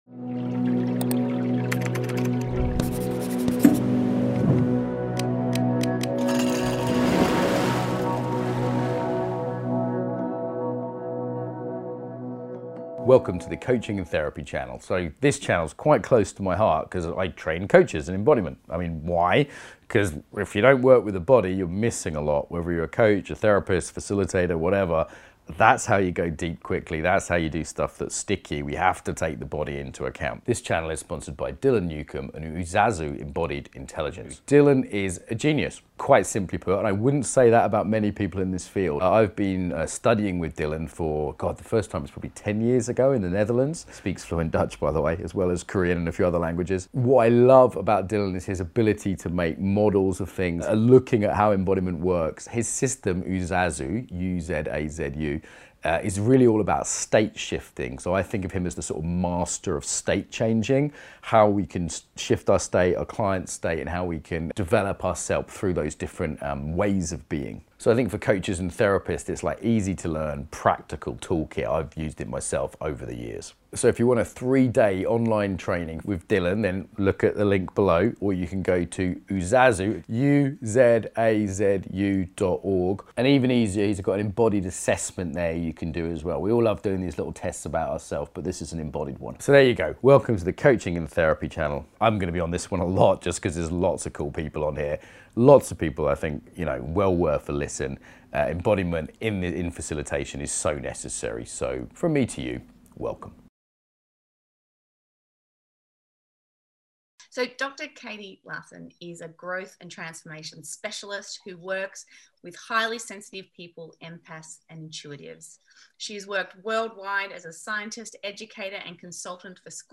Guided Practices